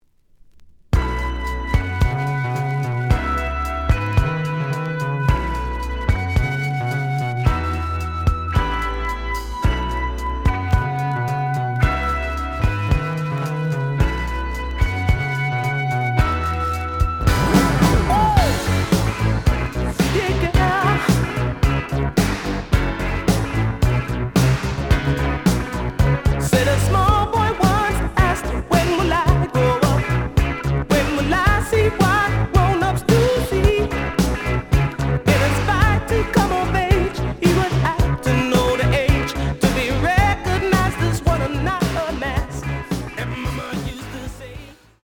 The audio sample is recorded from the actual item.
●Genre: Disco
●Record Grading: VG~VG+ (傷はあるが、プレイはおおむね良好。Plays good.)